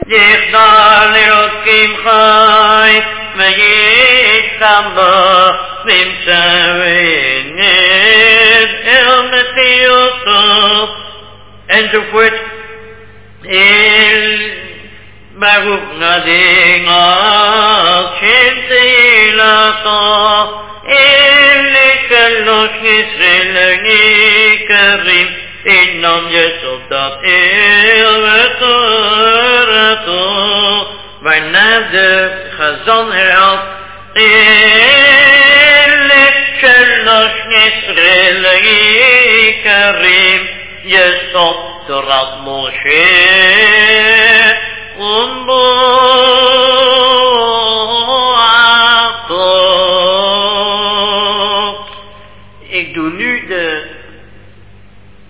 Chazzan , after ותורתו